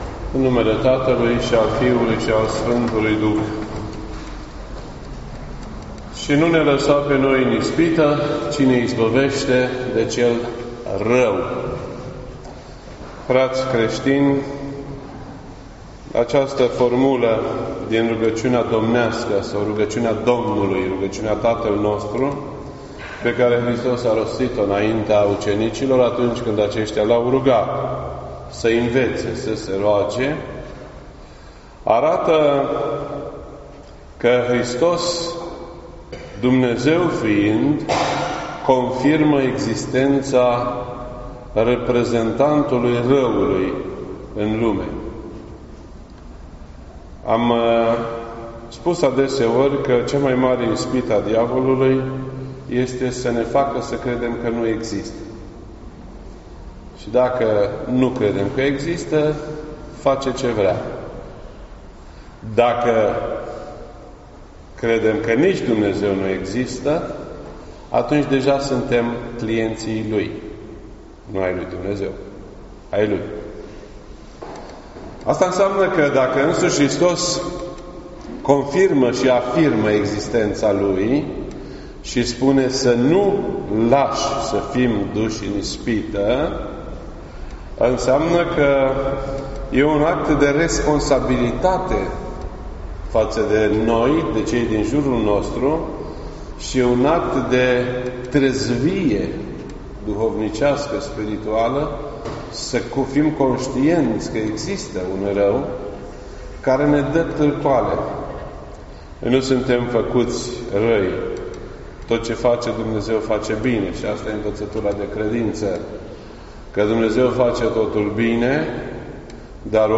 Predici ortodoxe in format audio